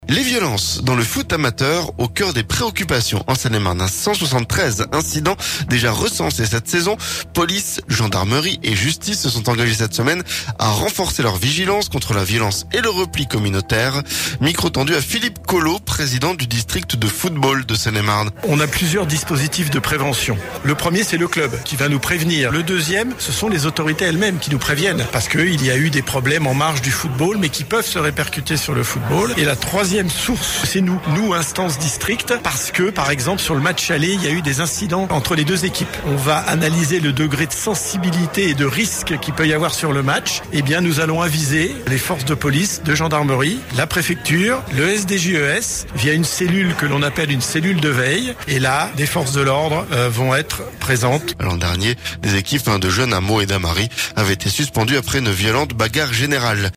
Micro tendu